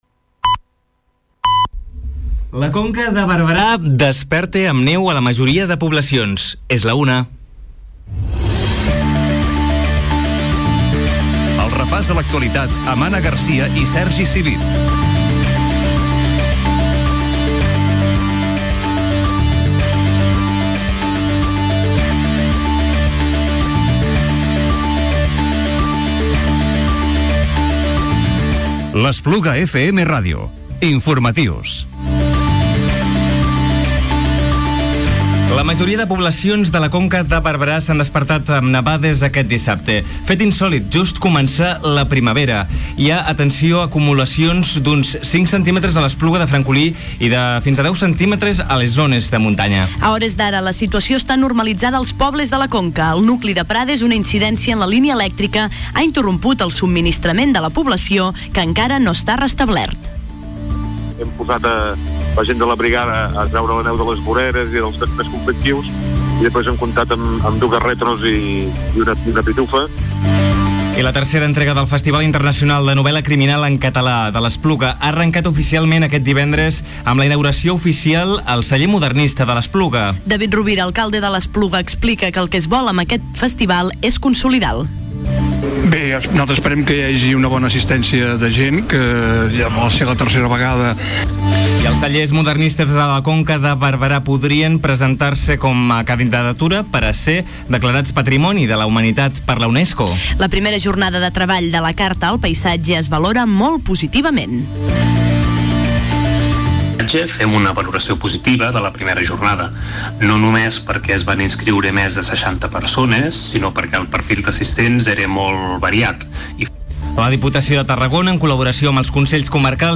Informatiu cap de setmana 25 i 26 de març 2017